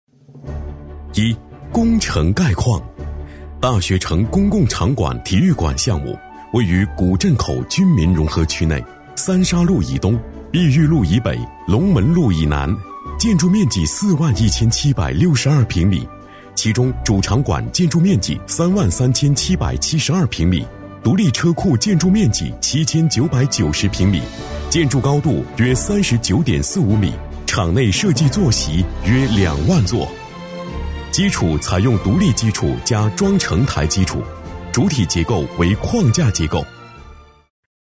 男70-项目汇报《体育馆》-大气沉稳
男70-项目汇报《体育馆》-大气沉稳.mp3